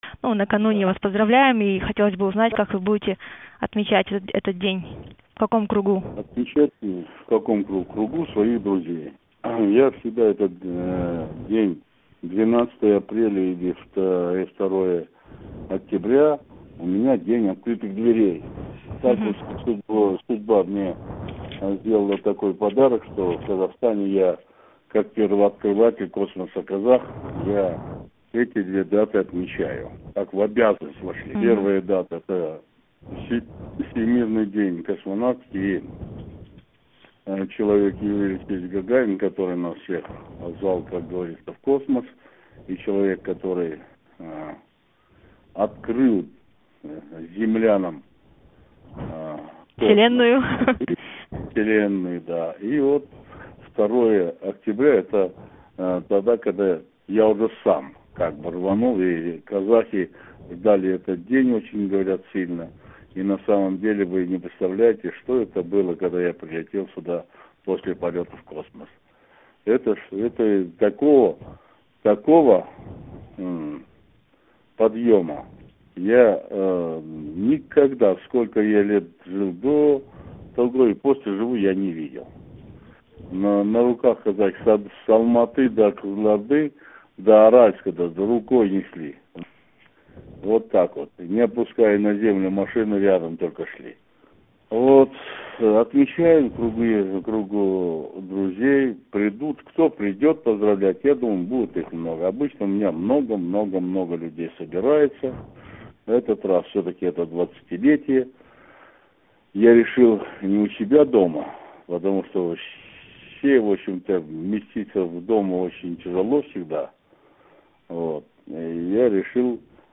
Интервью с Тохтаром Аубакировым